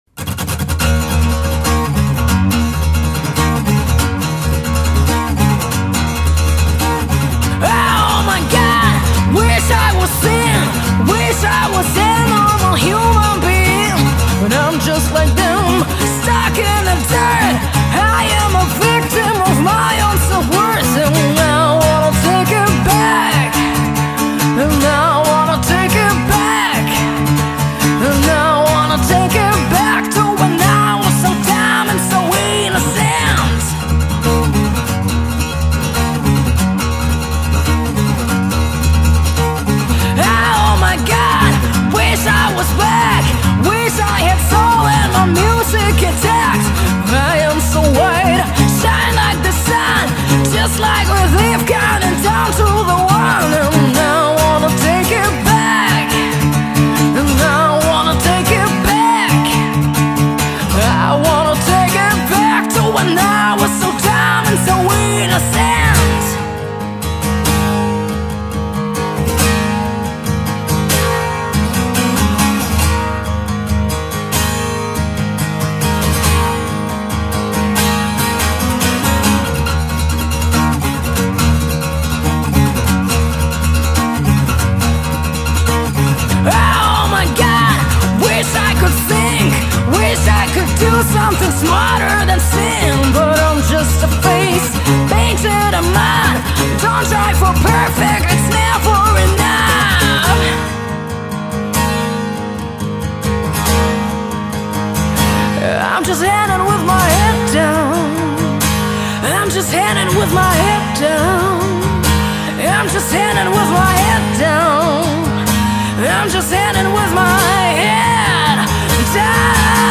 acoustic cover